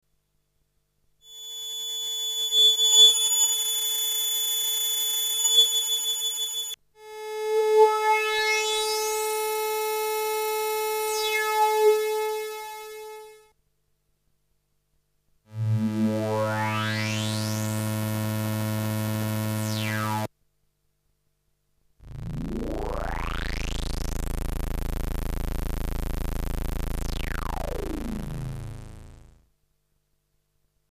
The filters are swept with the envelopes.
There are differences to the slope times, so that a spatial stereo effect happens. In this case I think the left channel uses low-pass filter and the right channel uses high-pass filter.
FilterSweep.mp3